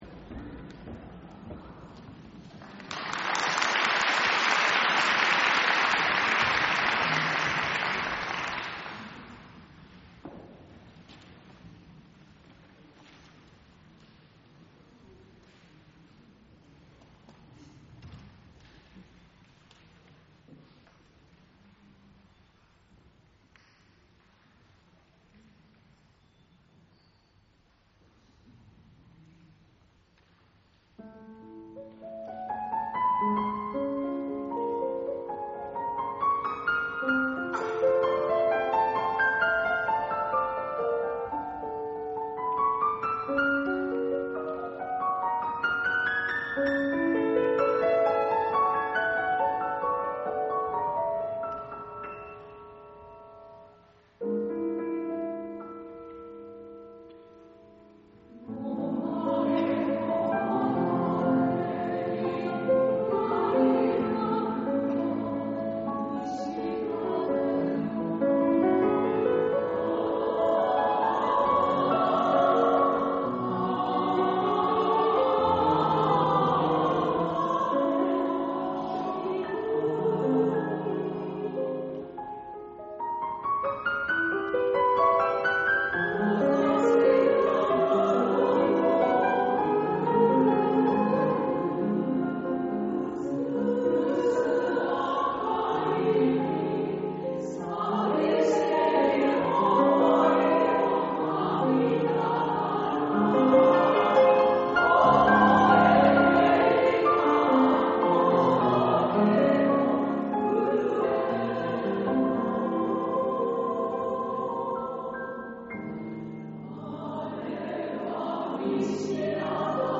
第６０回台東区合唱祭が、平成２７年１１月１５日（日）に東京藝術大学奏楽堂で開催されました。